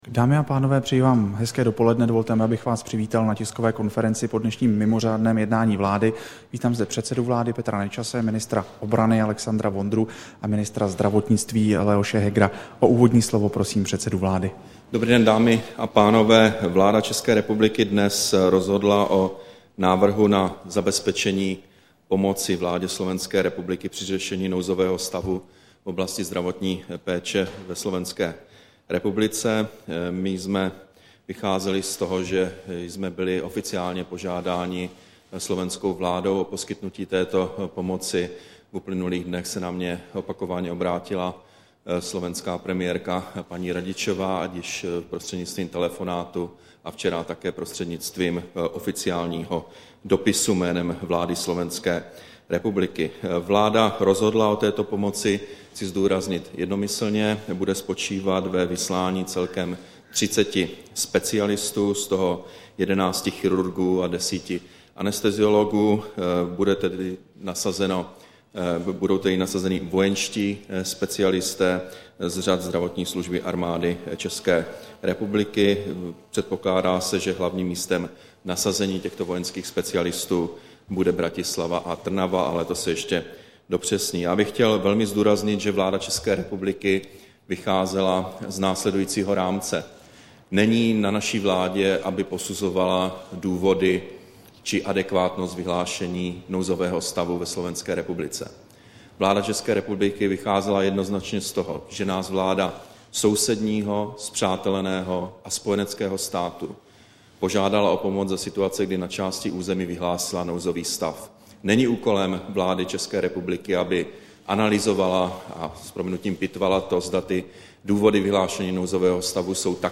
Tisková konference po mimořádném jednání vlády, 2. prosince 2011